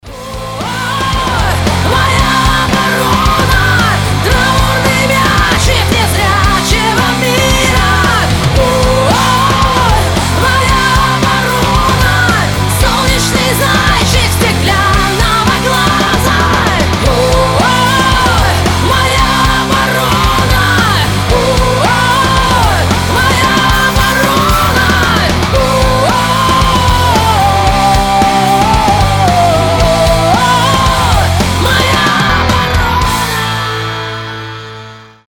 • Качество: 320, Stereo
громкие
мощные
Драйвовые
Cover
Alternative Metal
Alternative Rock